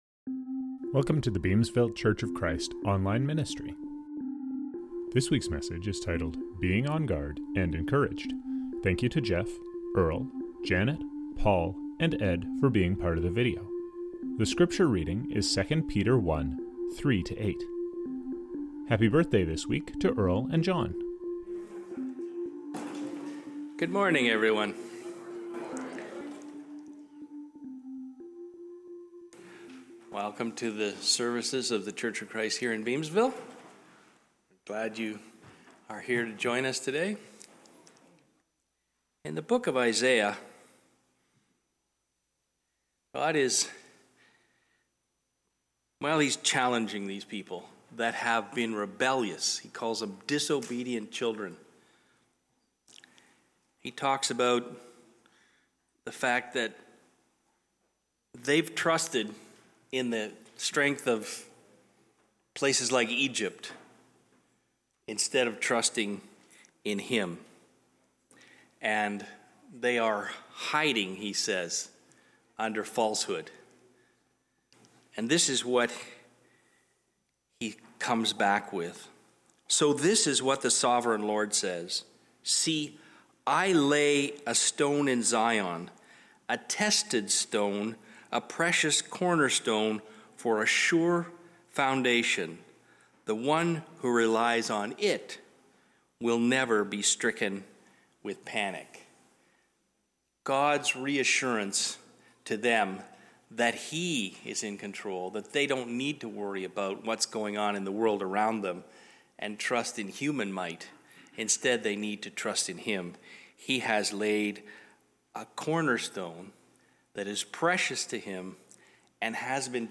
Songs from this service: We Praise Thee, O God